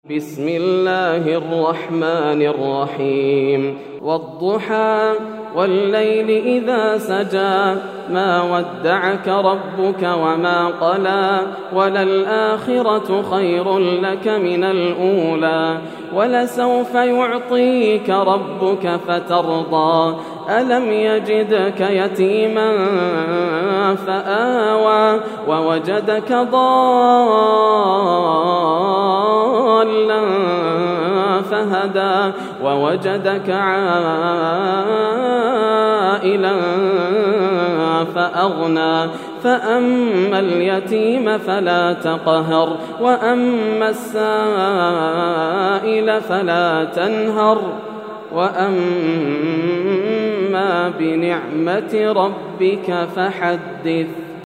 سورة الضحى > السور المكتملة > رمضان 1431هـ > التراويح - تلاوات ياسر الدوسري